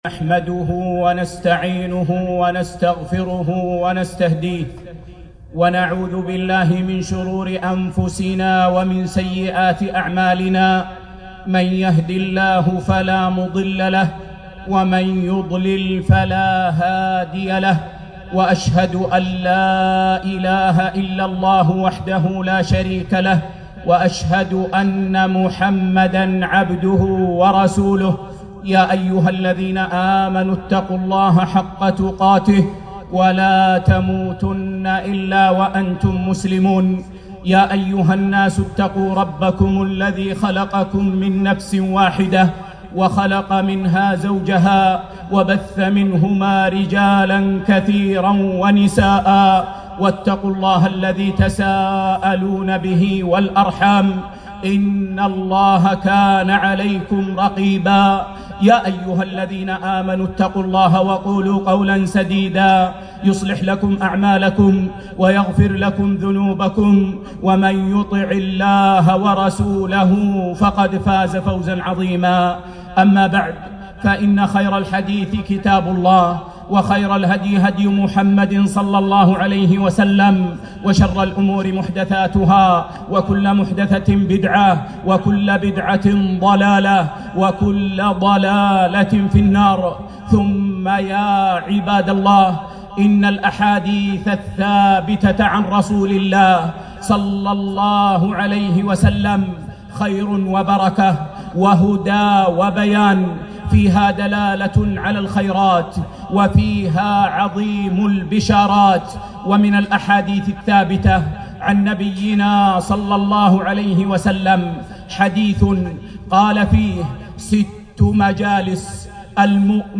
خطبة - ست مجالس